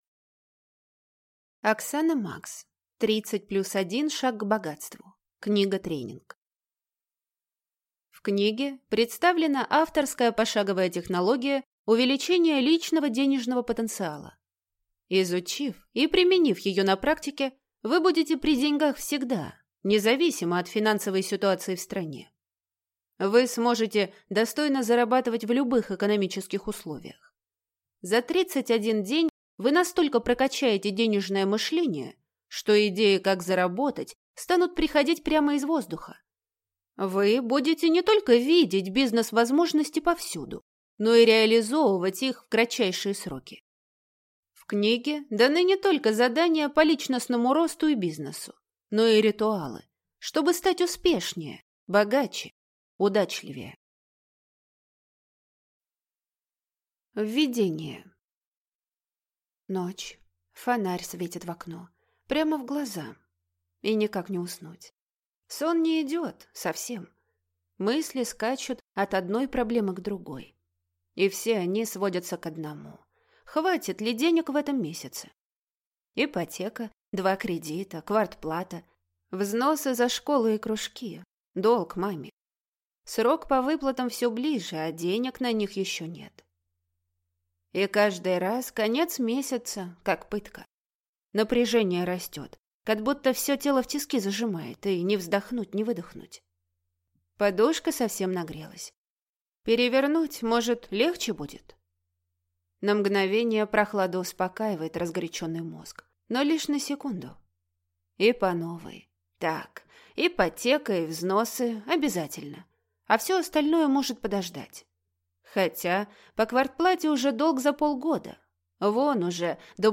Аудиокнига Книга-тренинг. 30+1 шаг к богатству | Библиотека аудиокниг